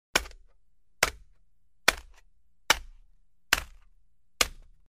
Звуки кирки
На этой странице собрана коллекция звуков ударов кирки по разным поверхностям.
Стук кирки о твёрдый грунт